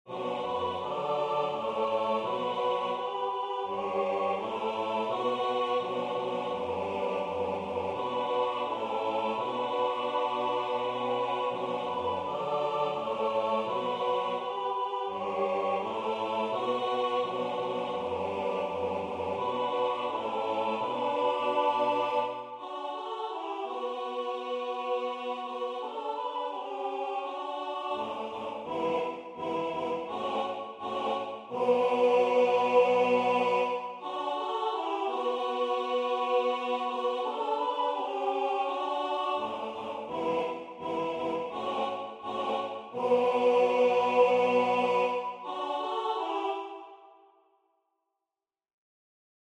Chants de Noël